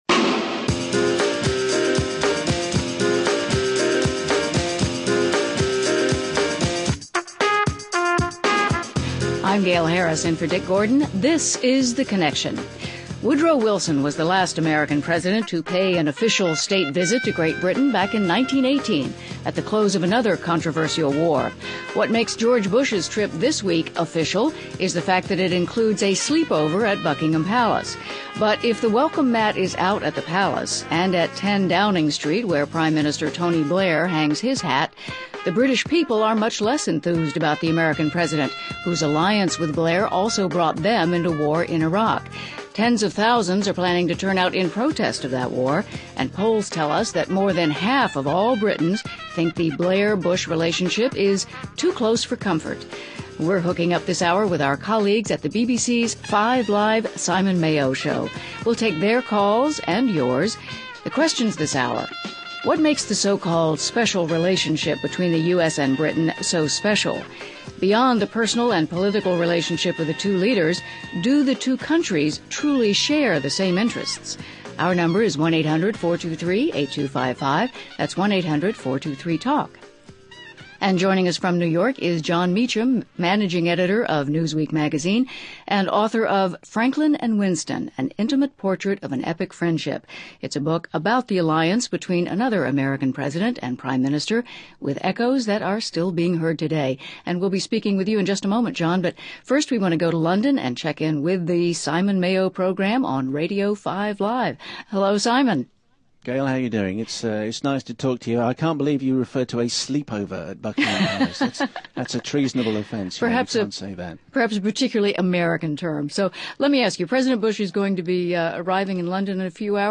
Guests: Jon Meacham, managing editor of Newsweek magazine and author of ‘Franklin and Winston': An Epic Friendship John Pienaar, chief political correspondent at BBC’s 5 Live.